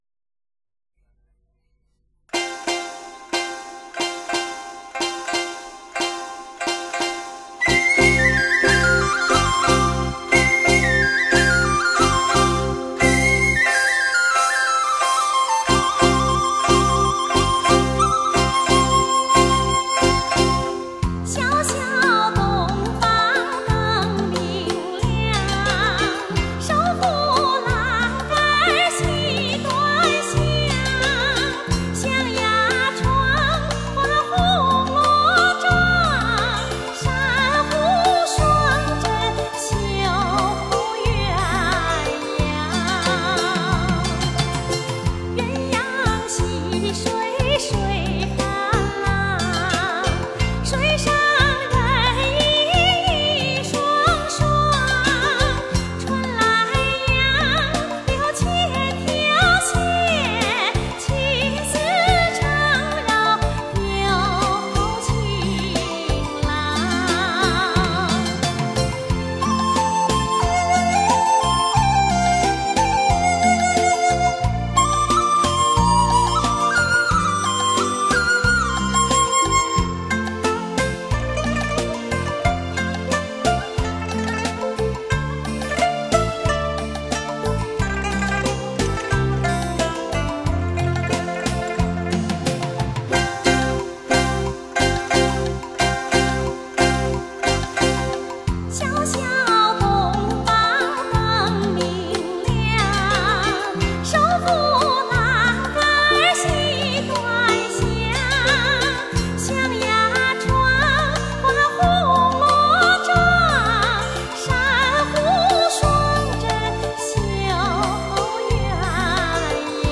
中国著名民歌歌唱家
江南民歌 情歌小调 东北 山东 江苏 河北 陕北民歌等
声音清澈悦耳
江南民歌